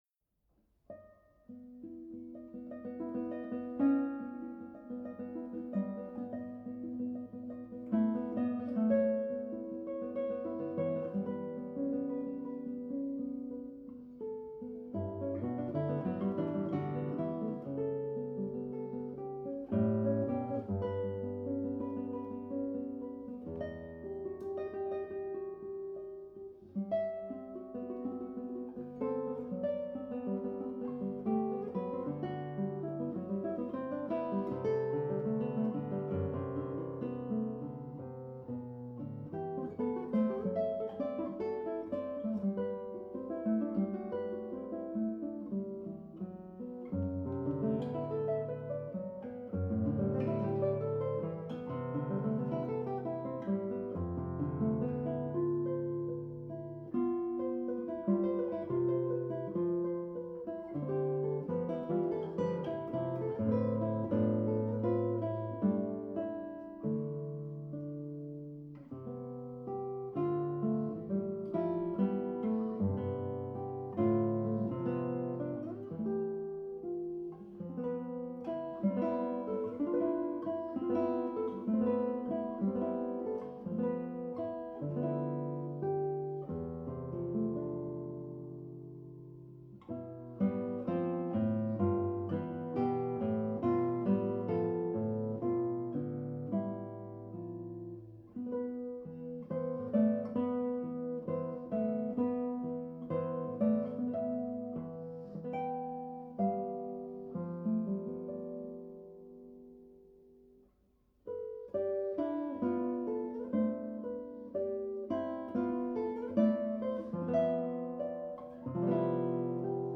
Allegretto semplice